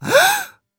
Surprised Gasp
A sharp, sudden gasp of surprise with quick inhale and brief vocal exclamation
surprised-gasp.mp3